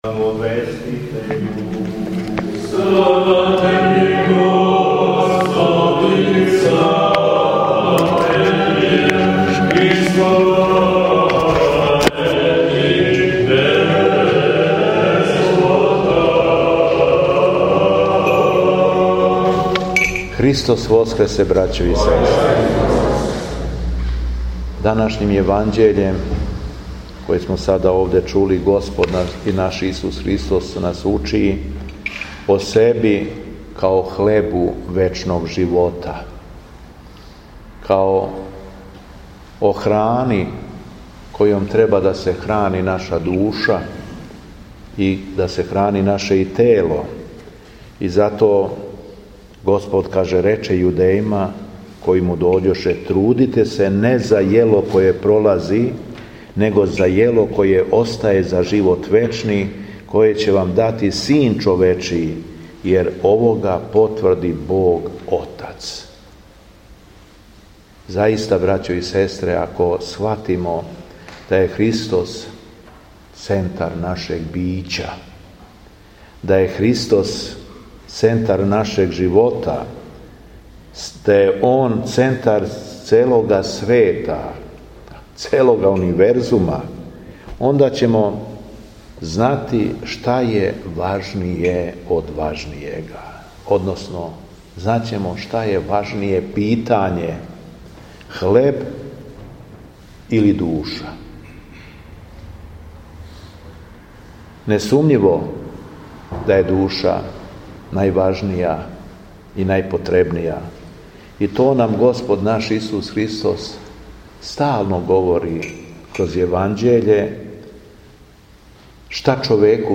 Беседа Његовог Преосвештенства Епископа шумадијског г. Јована
Уторак, 2. маја 2023. године, Његово Преосвештенство Епископ шумадијски Господин Јован служио је Свету Архијерејску литургију у храму Светог великомученика Пантелејмона у крагујевачком насељу Станово.